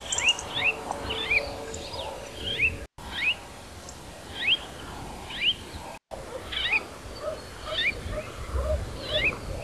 GALERIDA CRISTATA - CRESTED LARK - CAPPELLACCIA